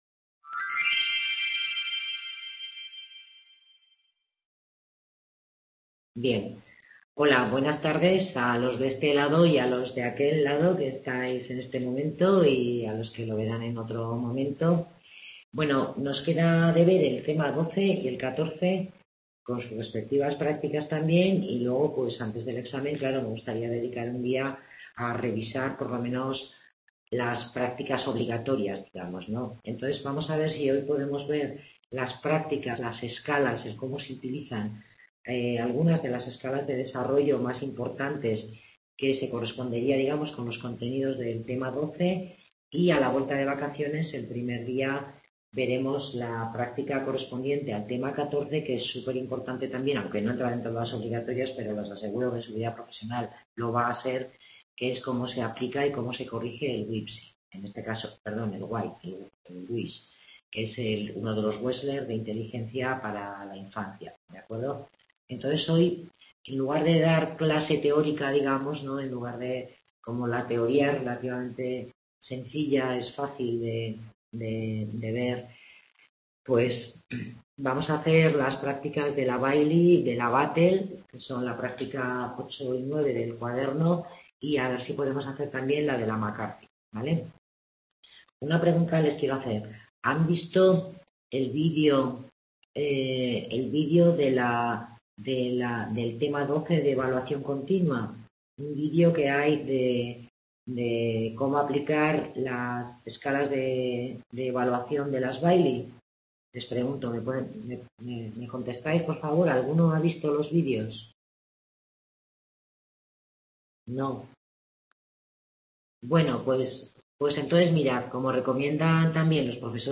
Tutoría grupal dedicada al tema 12, donde se revisan algunas escalas del desarrollo infantil y se revisa la práctica de las escalas Bayley y el screening de la escala Battelle